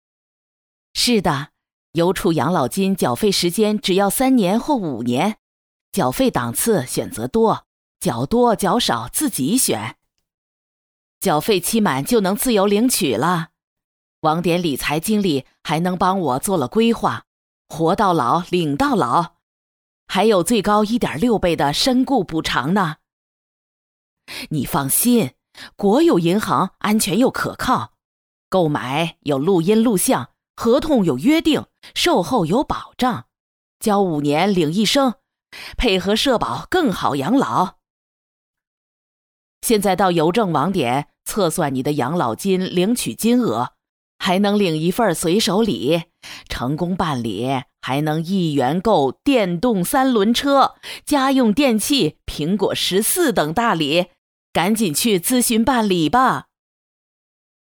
男49号-物理课件-探究平面镜成像时像距和物距的关系 男49年轻老师